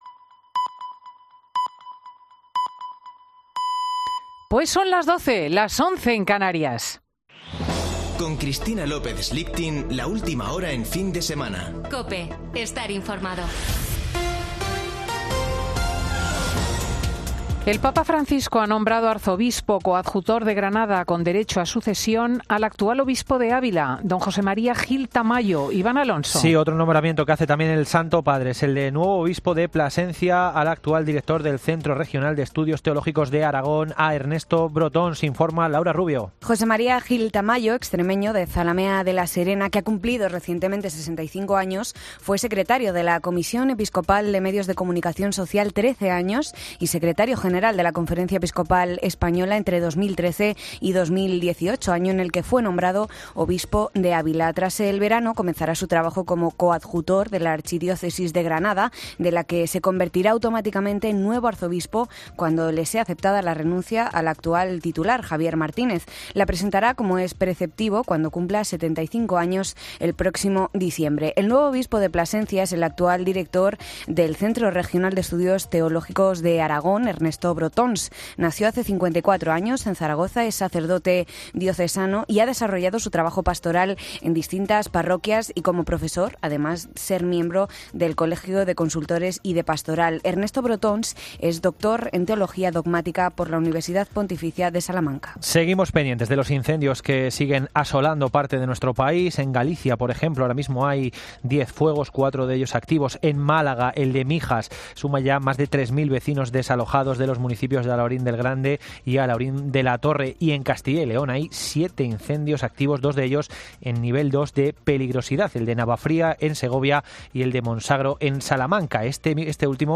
Boletín de noticias de COPE del 16 de julio de 2022 a las 12:00 horas